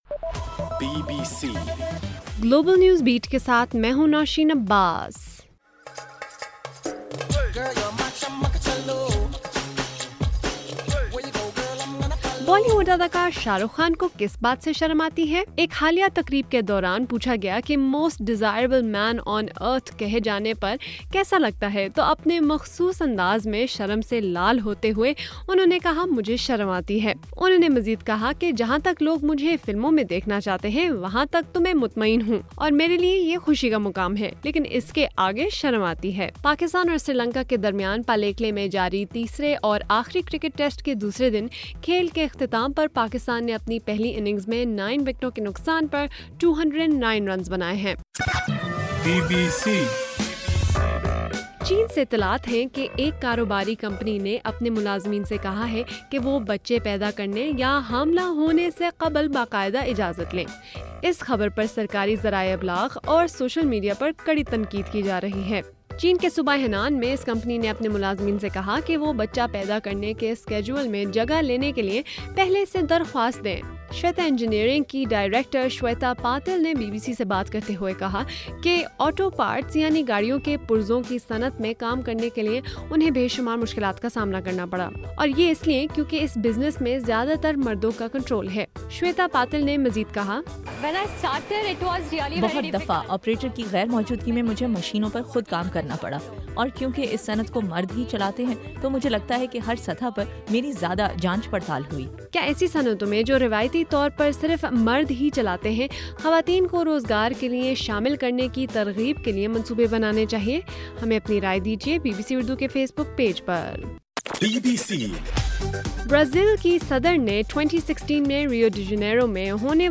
جولائی 4: رات 12 بجے کا گلوبل نیوز بیٹ بُلیٹن